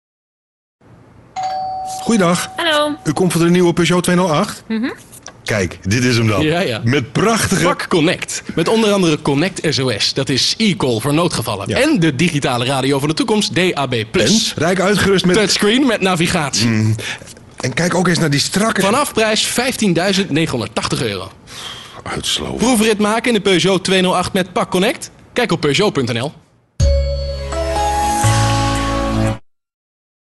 Hieronder enkele radiocommercials: